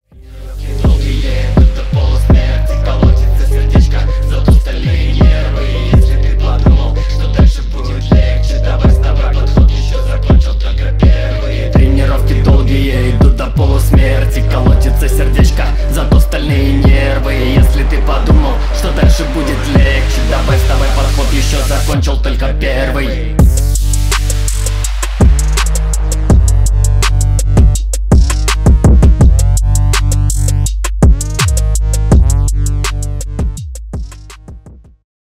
• Качество: 320 kbps, Stereo
Рэп и Хип Хоп
громкие